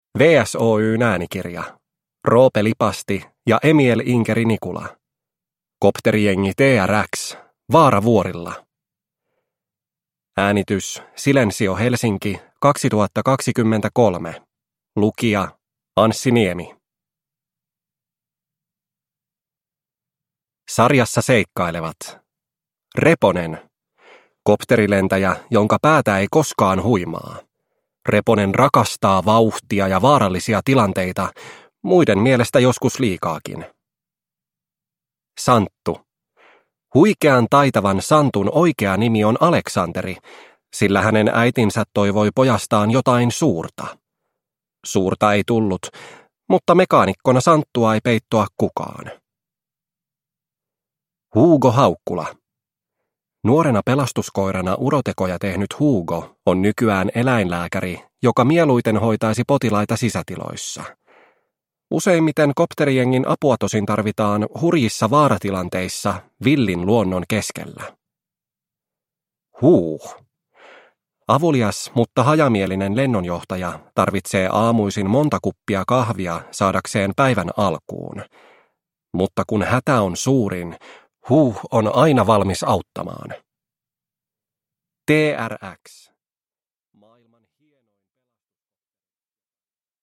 Kopterijengi T-RX 1: Vaara vuorilla – Ljudbok – Laddas ner